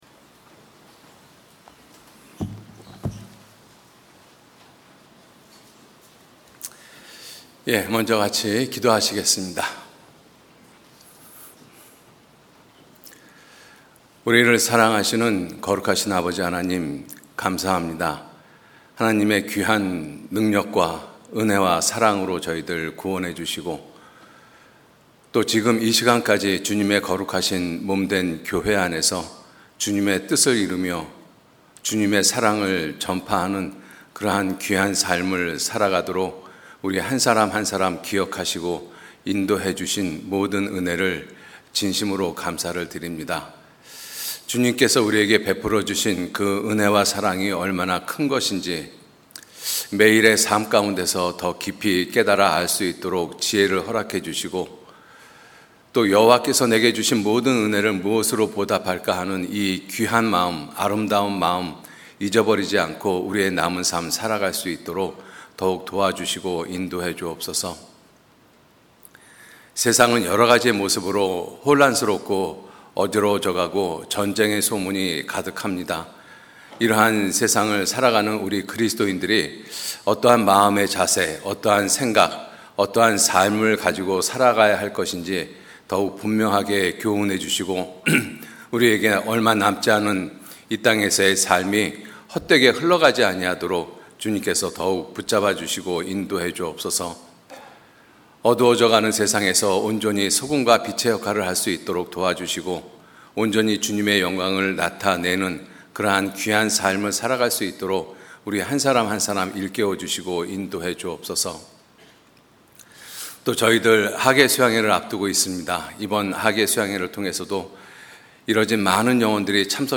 주일설교수요설교 (Audio)